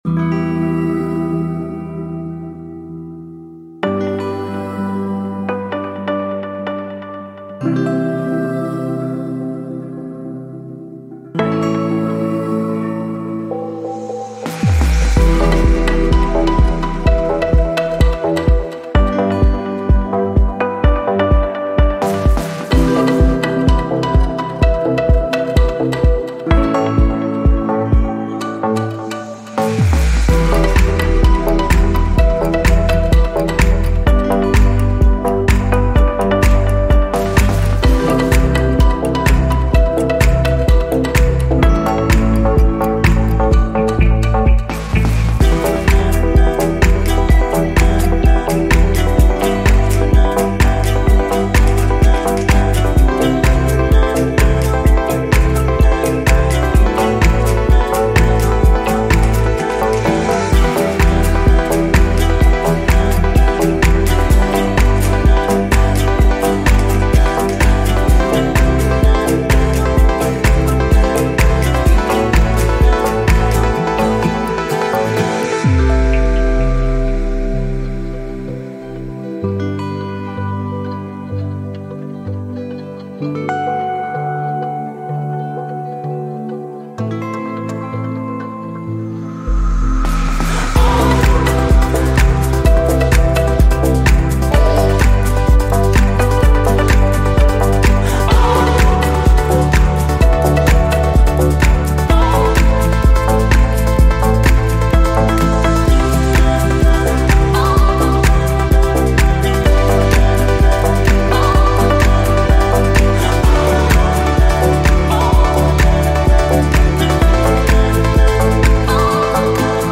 стандартная мелодия гудка
без слов